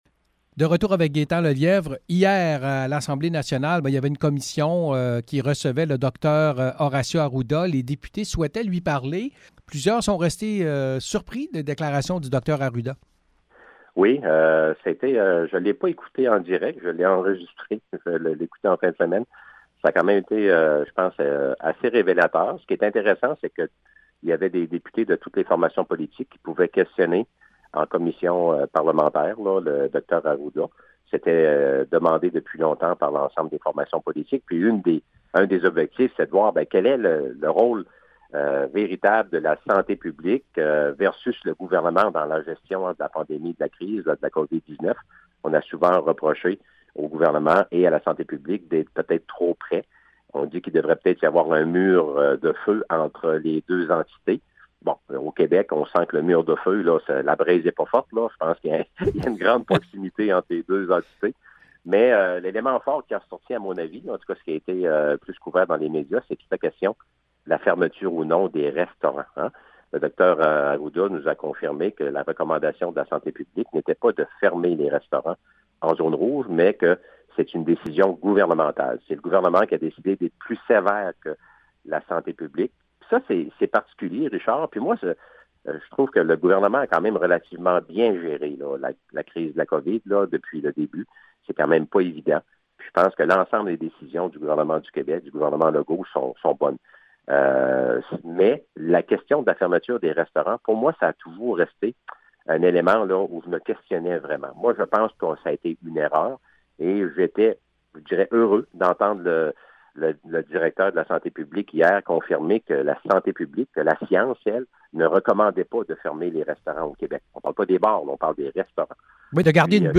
Aussi, chronique politique avec Gaétan Lelièvre qui revient sur des dossiers qui ont marqué la semaine.
Chronique avec Gaétan Lelièvre: